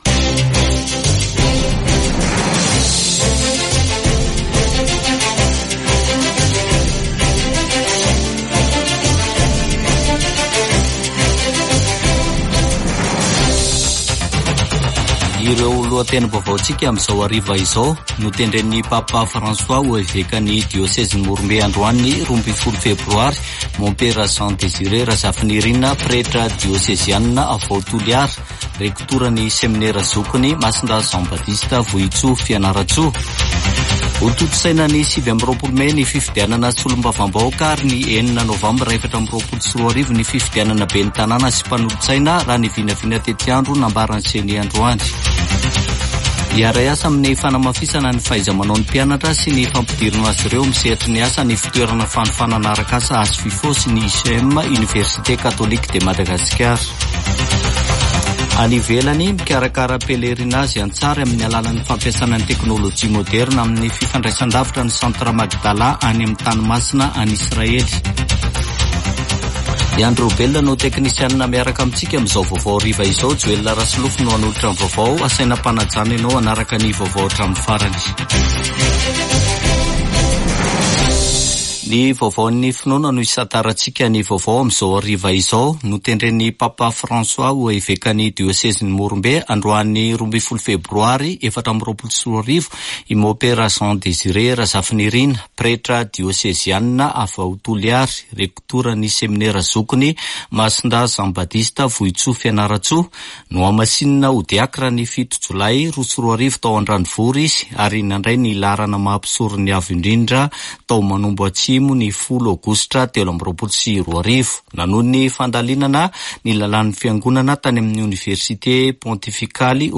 [Vaovao hariva] Alatsinainy 12 febroary 2024